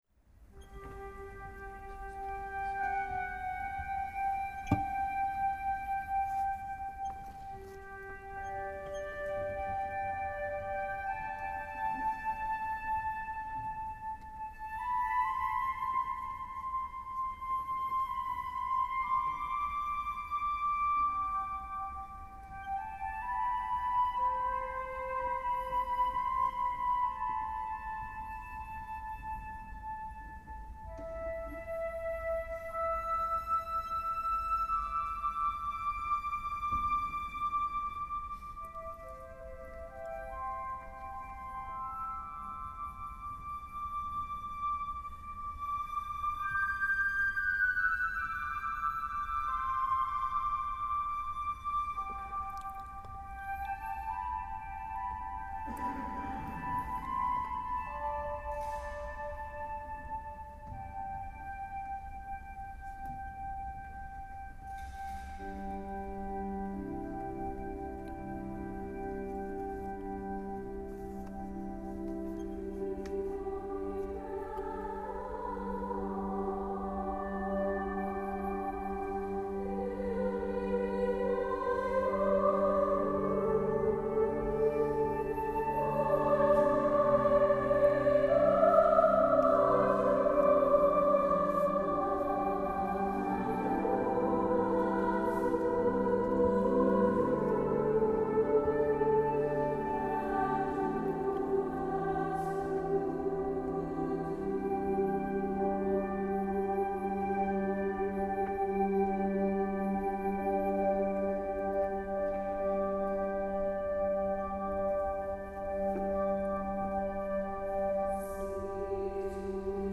for SSA Chorus, Flute, and Piano (2008)